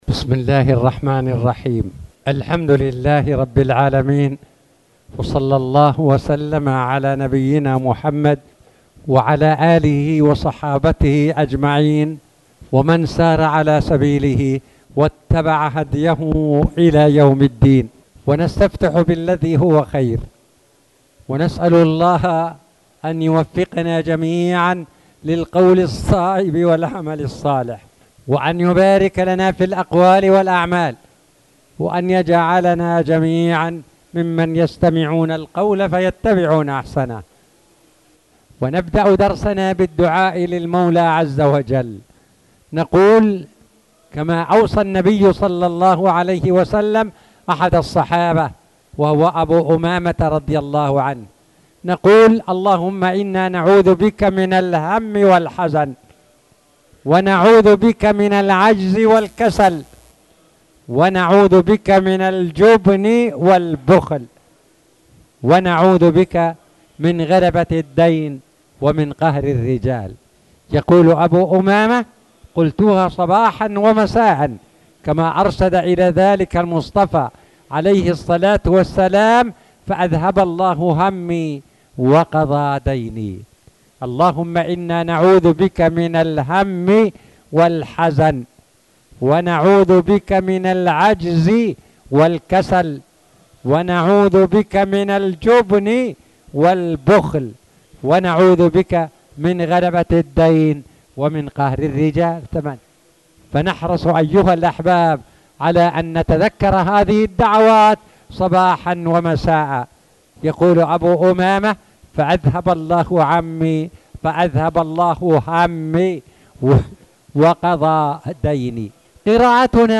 تاريخ النشر ١١ شعبان ١٤٣٨ هـ المكان: المسجد الحرام الشيخ